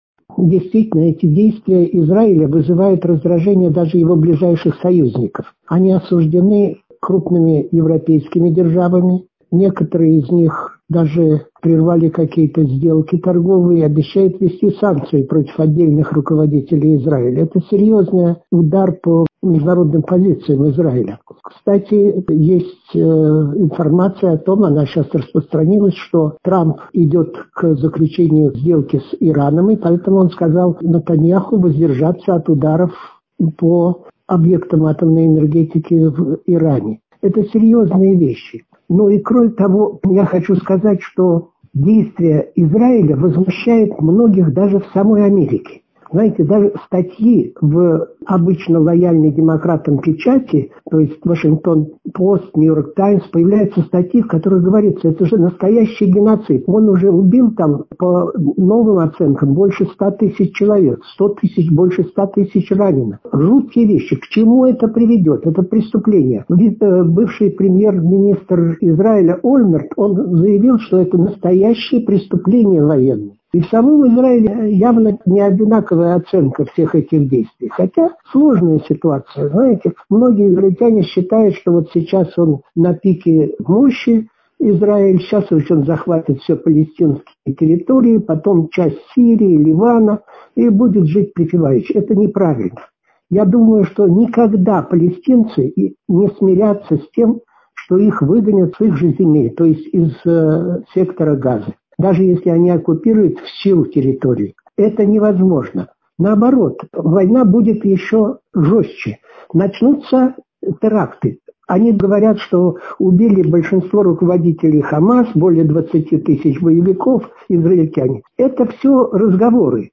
Чрезвычайный и полномочный посол, директор Центра партнёрства цивилизаций Института международных исследований МГИМО(У) МИД России Вениамин Попов в интервью журналу «Международная жизнь» рассказал о действиях Израиля в секторе Газа: